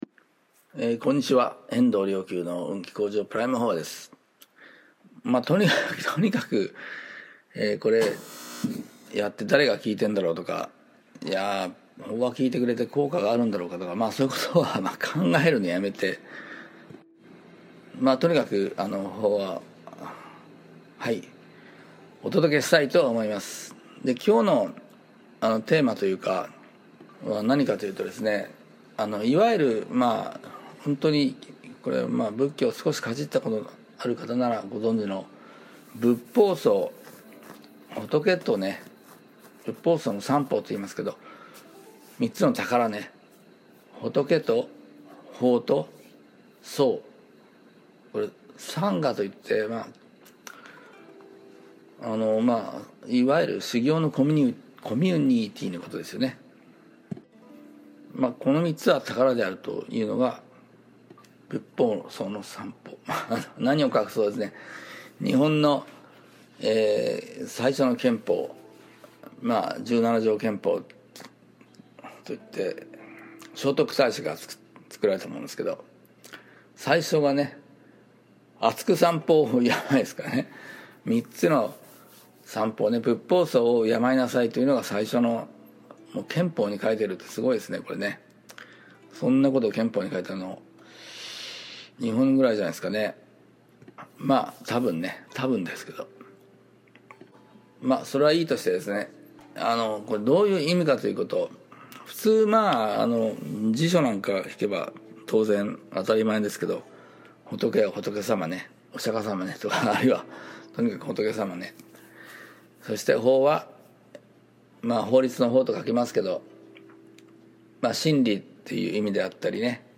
「仏法僧」は、日本最初の憲法って！？（１０分、法話）